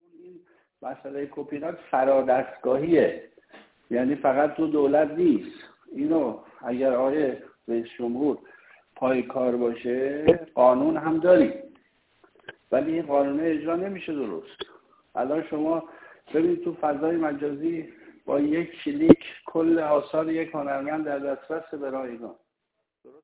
صحبت‌های رئیس خانه موسیقی در مراسم افطاری رئیس جمهور + صوت